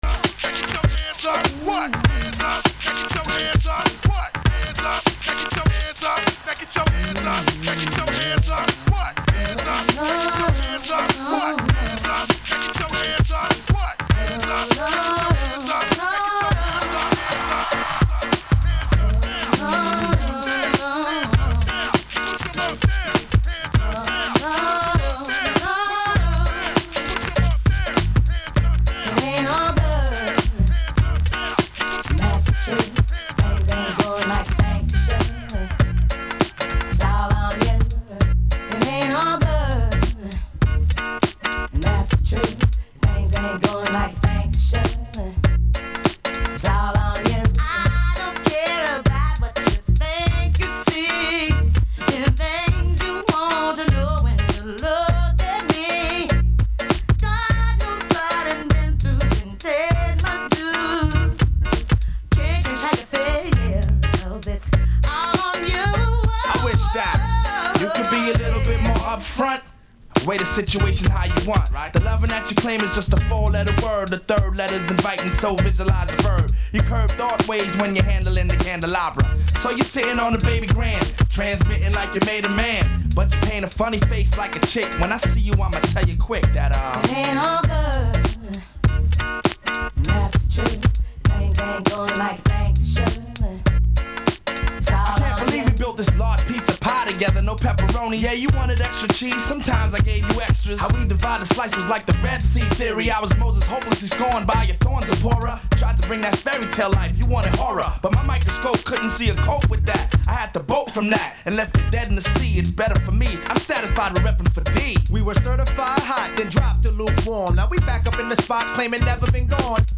L’interview
L’interview commence avec un certain décalage (vers 5 min 25 secondes).